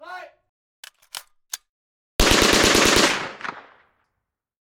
На этой странице представлены аудиозаписи, имитирующие звуки выстрелов.
Звук расстрела заключенного немцами